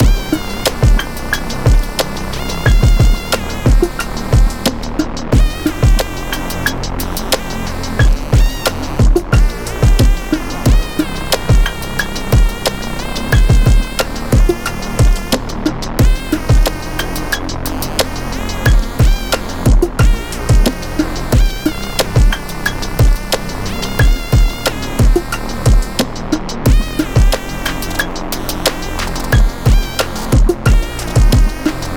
C Minor
Aggressive (Onyx)
Game Voice